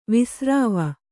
♪ visrāva